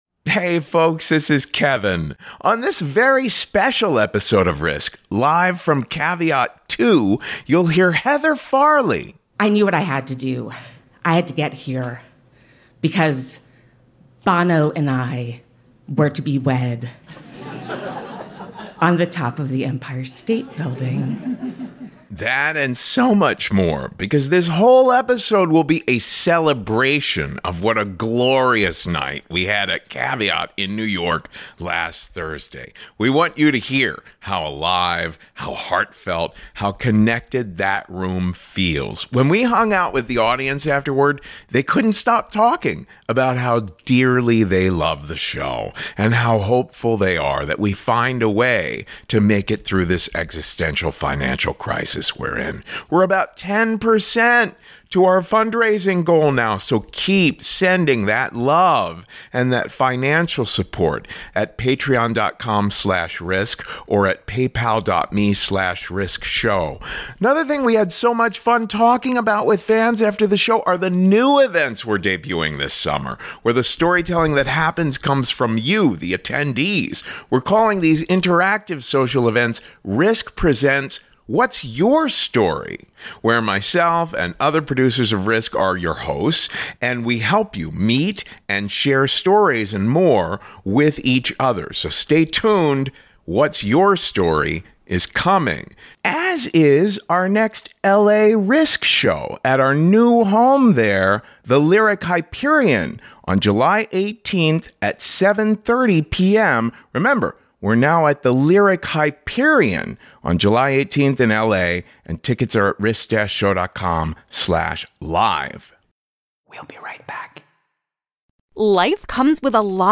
Live From Caveat 2!
Listen to how amazing our live shows at Caveat in NYC are.